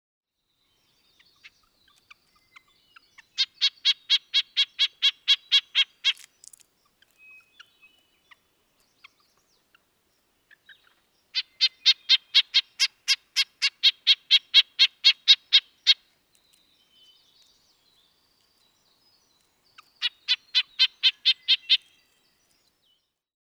Подборка включает разные варианты голосов, записанных в естественной среде обитания.
Звук птицы: Канадский поползень (Red-breasted nuthatch)